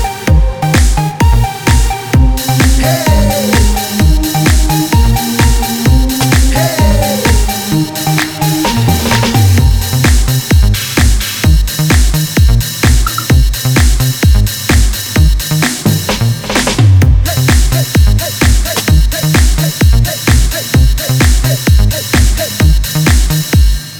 For Solo Male R'n'B / Hip Hop 4:13 Buy £1.50